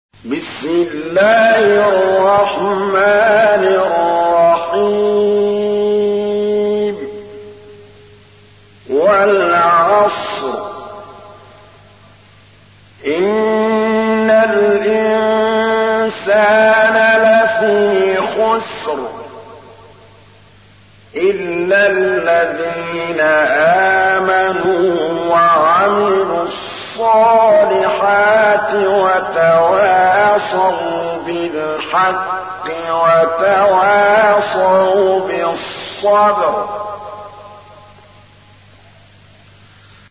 تحميل : 103. سورة العصر / القارئ محمود الطبلاوي / القرآن الكريم / موقع يا حسين